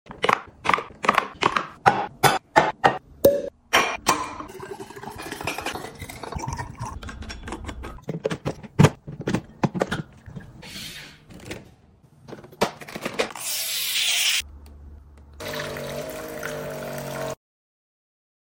Home Drink Machine Set Up Sound Effects Free Download